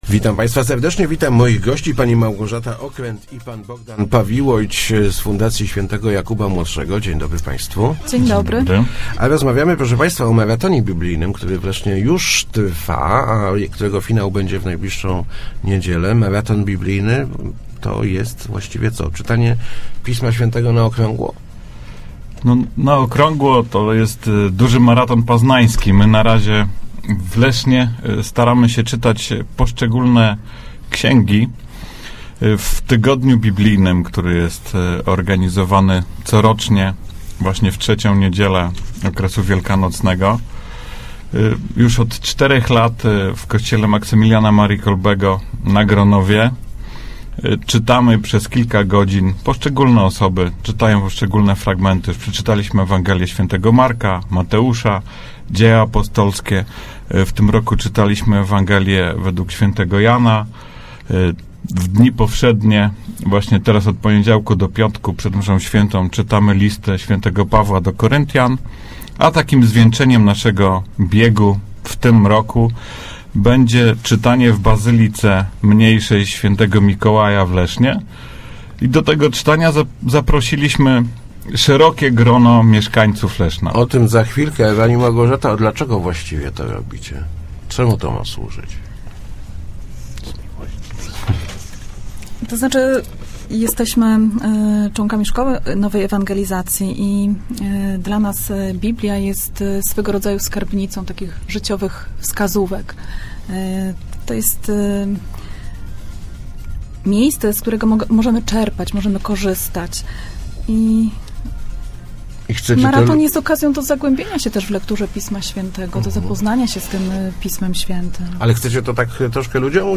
Start arrow Rozmowy Elki arrow Maraton Biblijny w Lesznie